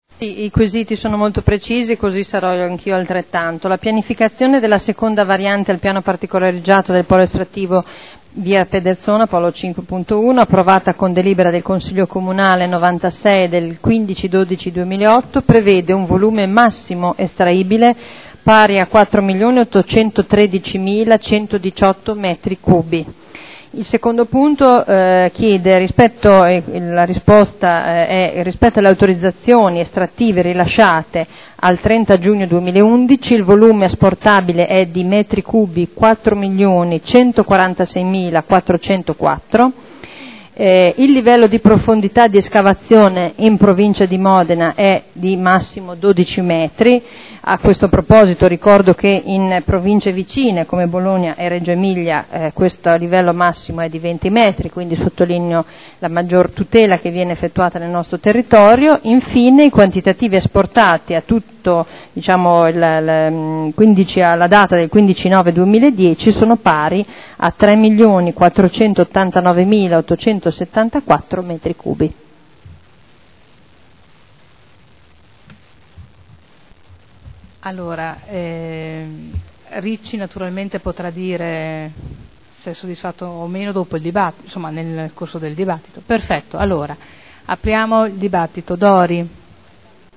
Simona Arletti — Sito Audio Consiglio Comunale
Seduta del 14/07/2011.